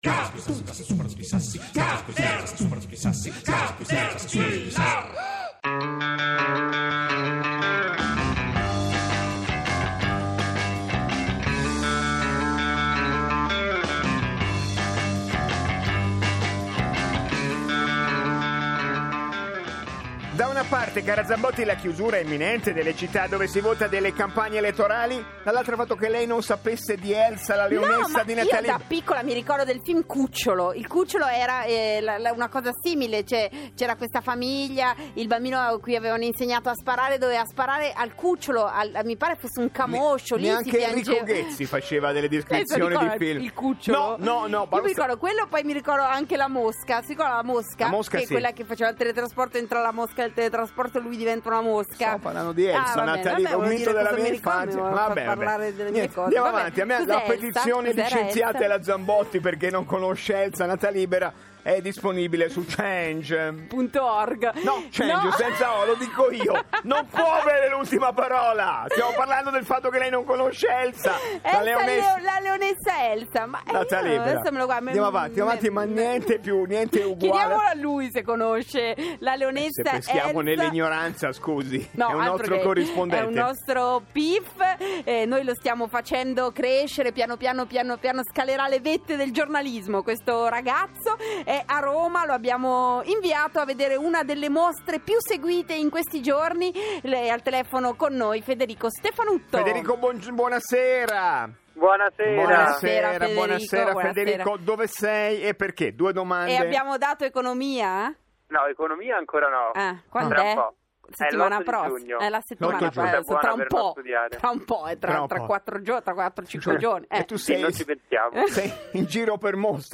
INTERVISTE IN RADIO: